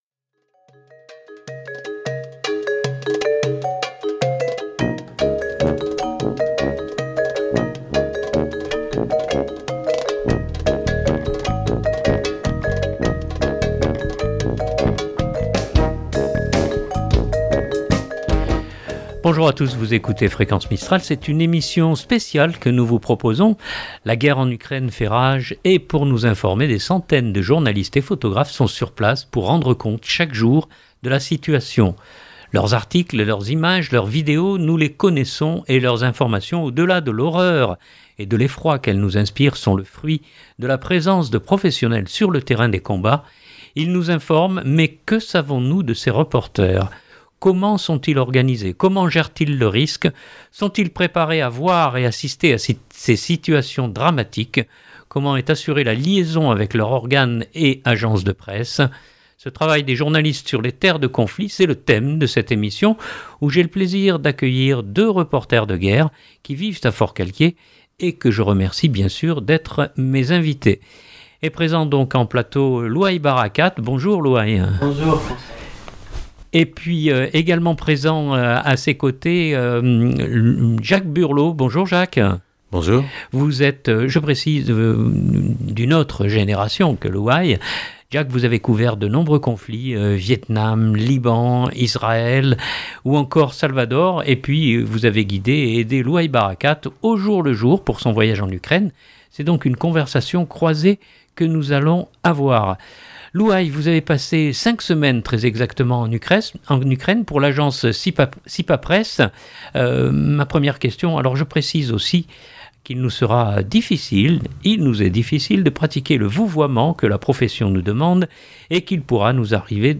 C’est donc une conversation croisée que nous allons avoir.